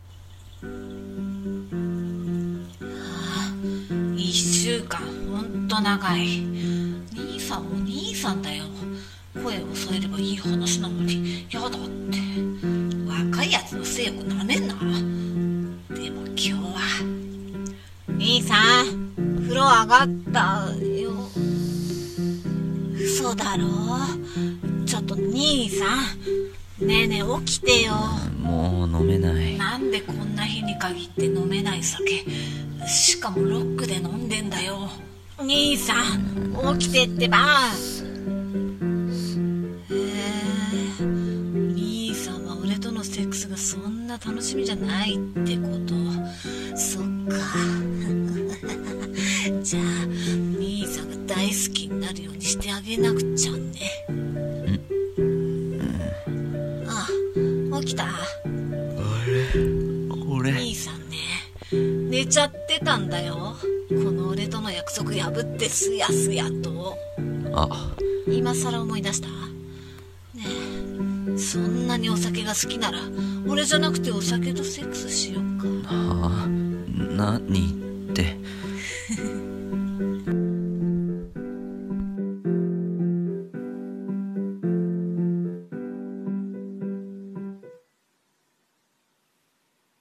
R15 BL声劇 台本「氷:前編」